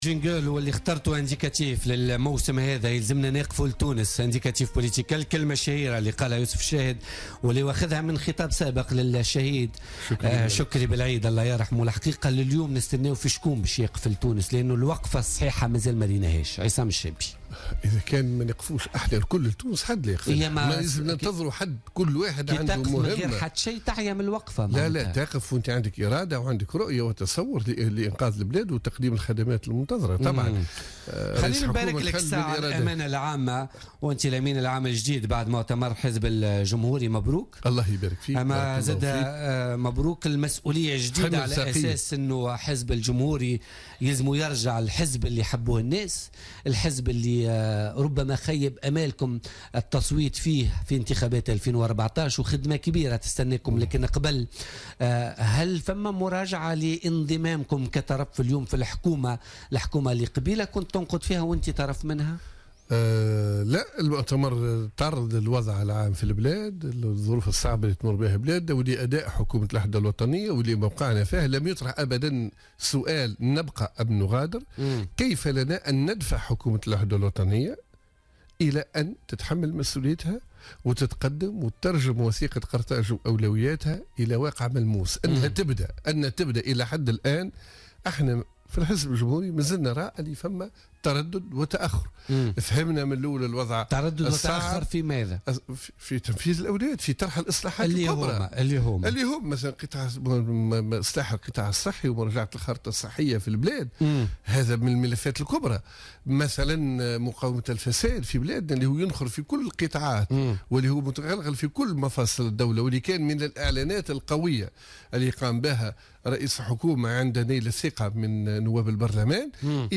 أكد الأمين العام للحزب الجمهوري عصام الشابي ضيف بوليتيكا اليوم الخميس 16 فيفري 2017...